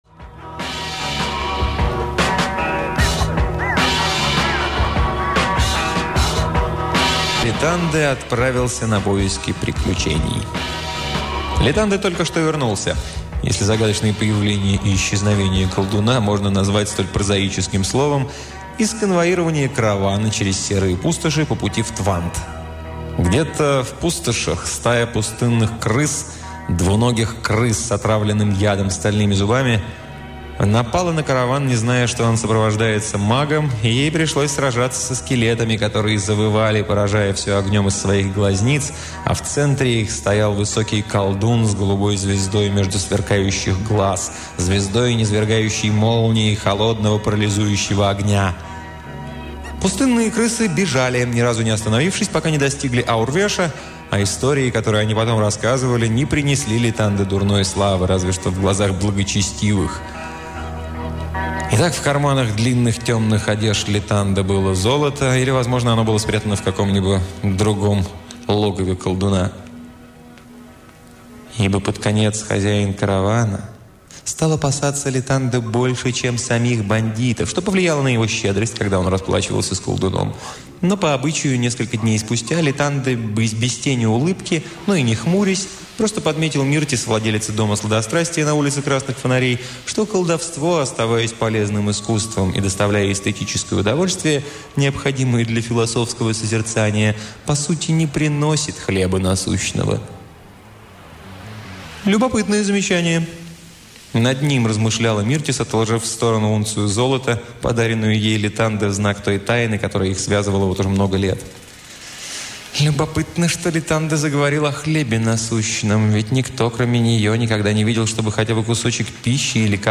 Аудиокнига Марион Зиммер Брэдли — Секрет голубой звезды